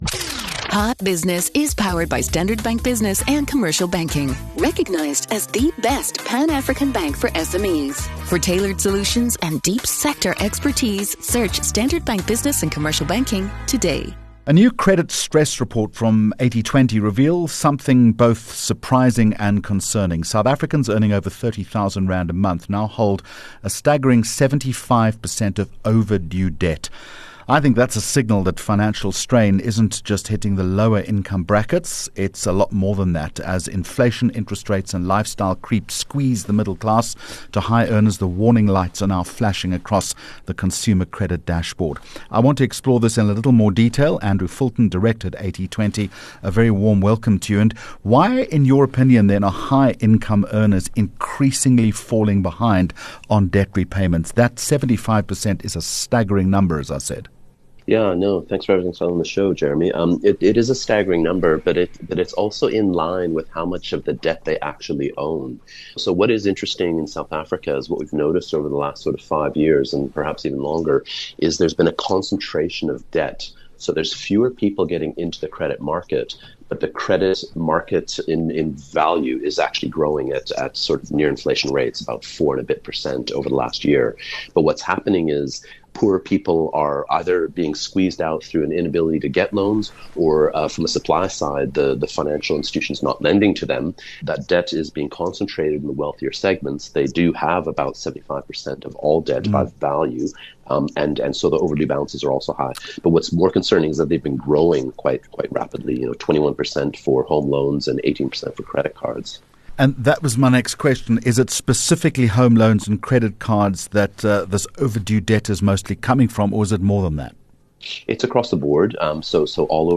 Hot Business Interview